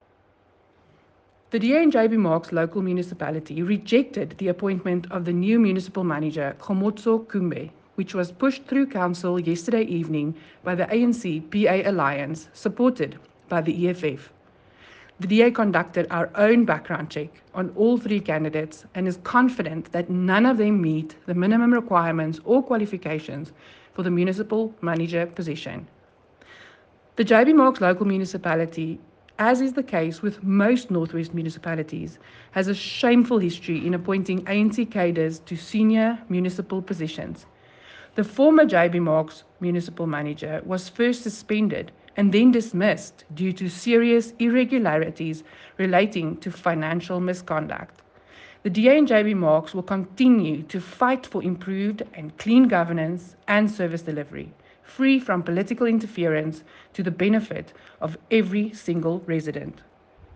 Note to Broadcasters: Please find a linked soundbite in
English by Cllr Johni Steenkamp.